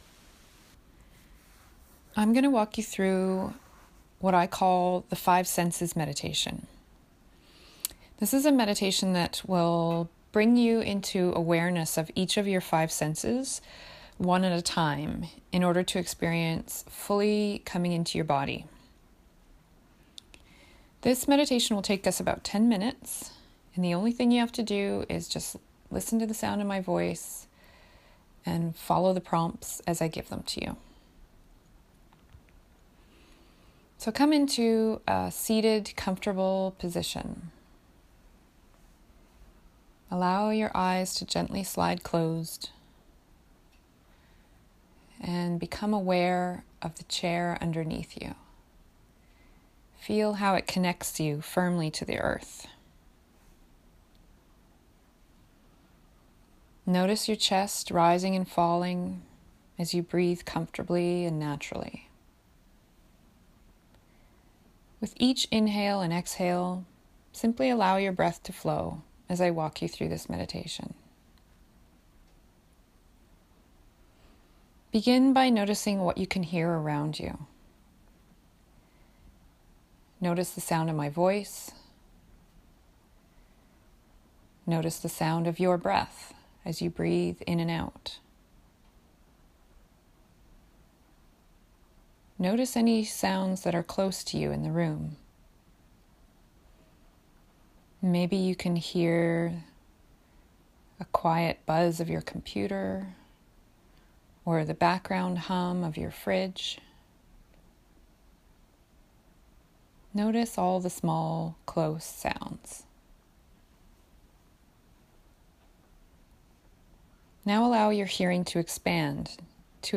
5 Senses Meditation